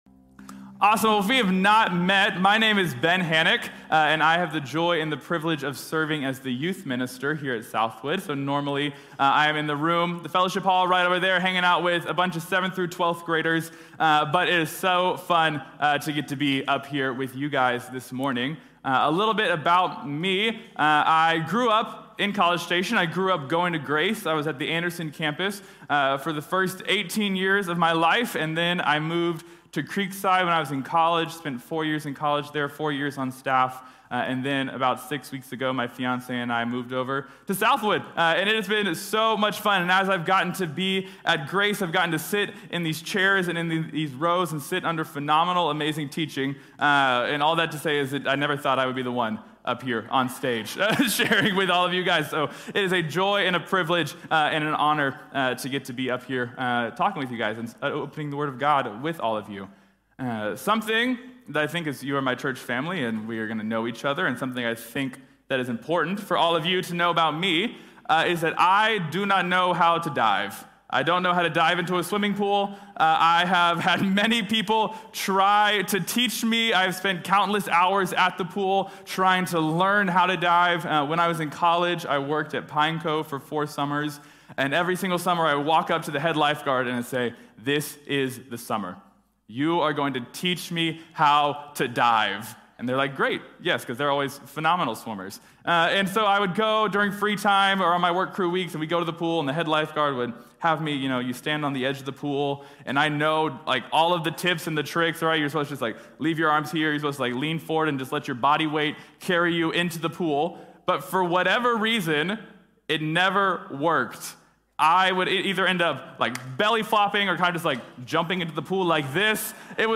Josías | Sermón | Iglesia Bíblica de la Gracia